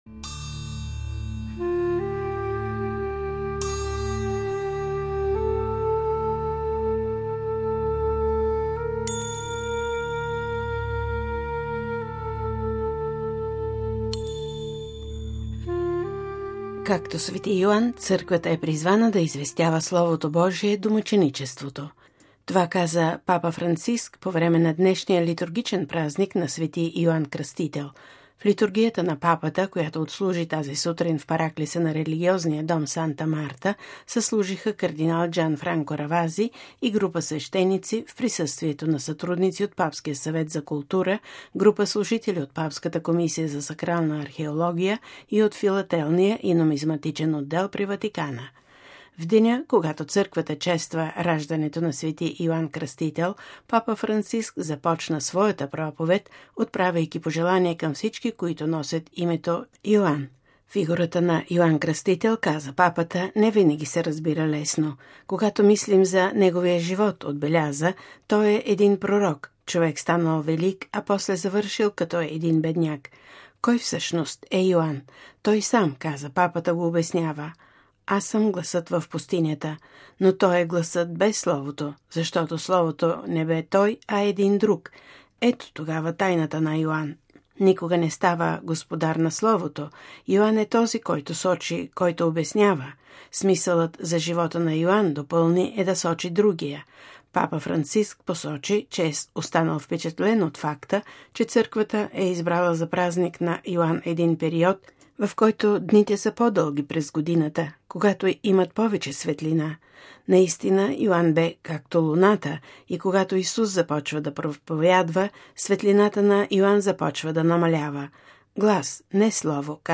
Това каза Папа Франциск по време на днешния литургичен празник на Св.Йоан Кръстител. В литургията на Папата, която отслужи тази сутрин в параклиса на религиозния Дом Санта Марта, съслужиха кард. Джанфранко Равази и група свещеници в присъствието на сътрудници от Папския съвет за култура, група служители от Папската комисия за сакрална археология и от Филателния и Нумизматичен отдел при Ватикана.